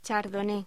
Locución: Chardonnay
voz